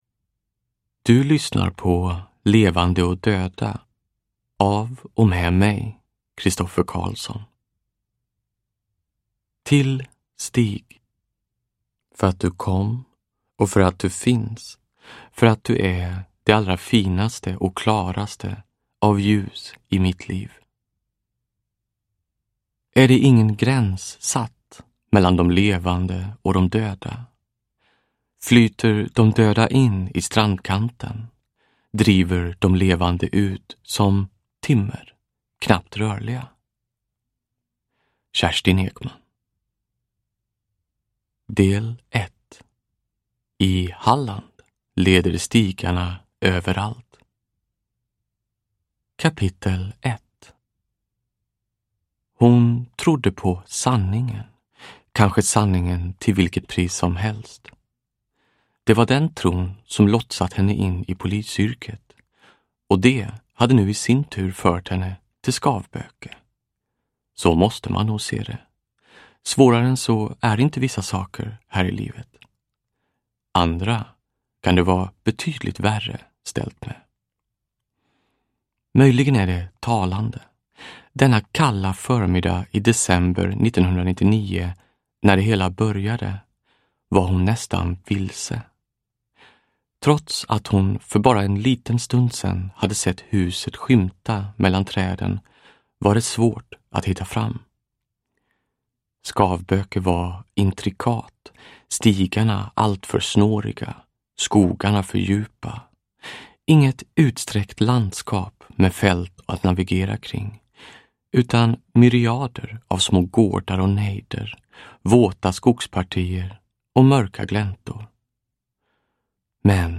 Levande och döda – Ljudbok – Laddas ner
Uppläsare: Christoffer Carlsson